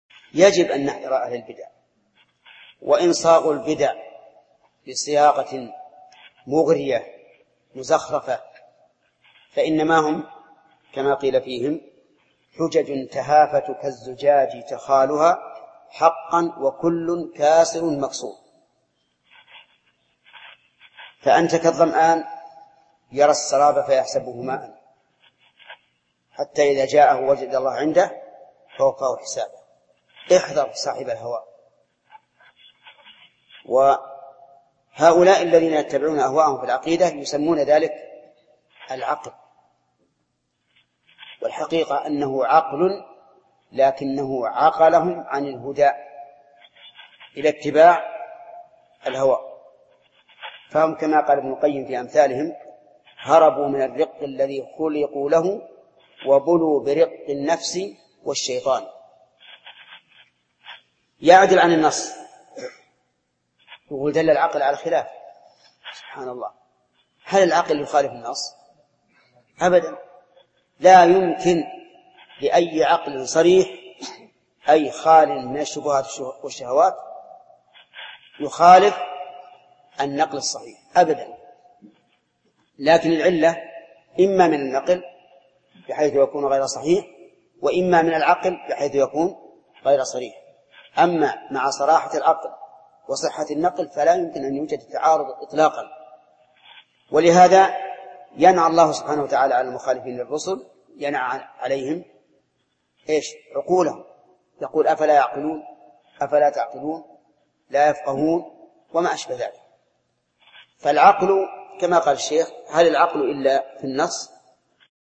Format: MP3 Mono 22kHz 32Kbps (VBR)